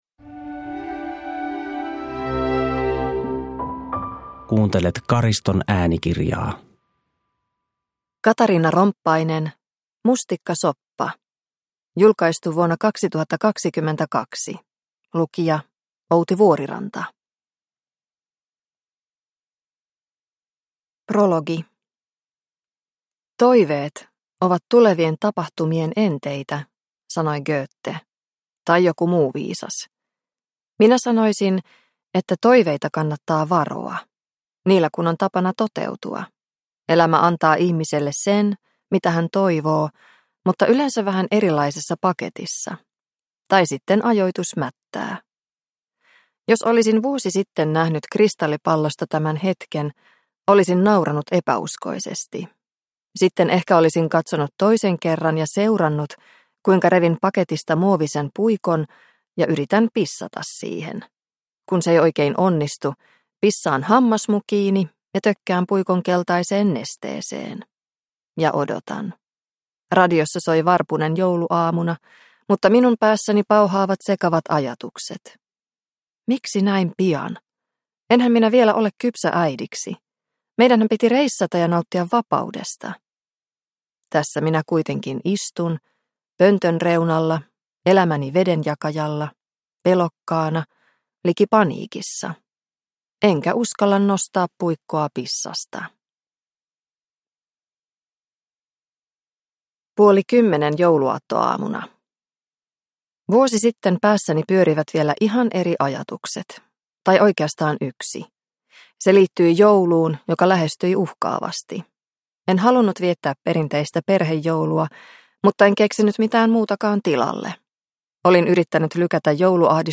Mustikkasoppa – Ljudbok – Laddas ner